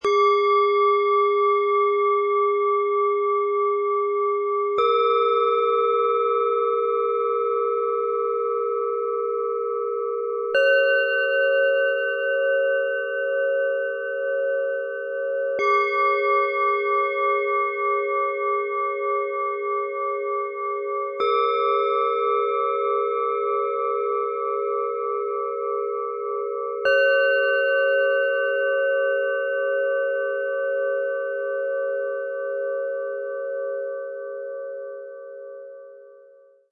Helle Klarheit & ruhige Zentrierung - 3 Klangschalen für Meditation & Raumklang
Die drei Schalen entfalten einen hellen, stabilen und zentrierenden Klang, der sich besonders gut für Meditation und achtsame Raumarbeit eignet.
Trotz ihrer kompakten Größe erzeugen die Schalen einen klaren, tragenden Ton, der ruhig im Raum steht.
Die größte Schale besitzt einen klaren, hellen und zugleich beruhigenden Ton.
Diese Schale schwingt ruhig, dabei freundlich und ausgleichend.
Ihr Klang zieht sich ruhig nach unten und rundet das Zusammenspiel der drei Schalen harmonisch ab.
In unserem Sound-Player - Jetzt reinhören können Sie den Original-Klang genau dieser Schalen in Ruhe anhören.
So bekommen Sie einen authentischen Eindruck vom hellen, klaren und stabil stehenden Klang, der sich besonders gut für Meditation und Raumklang eignet.
MaterialBronze